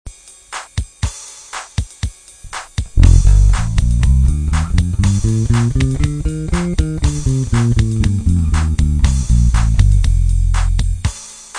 Cliquer sur Ex et vous  aurez un exemple sonore en F (Fa).
MIXOLYDIEN